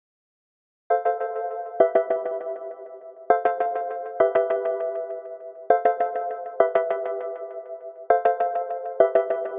拖曳式风琴延时
描述：这种拖曳的效果给乐器带来了一点诡异的感觉。
Tag: 100 bpm Chill Out Loops Organ Loops 1.62 MB wav Key : Unknown